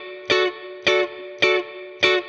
DD_StratChop_105-Dmaj.wav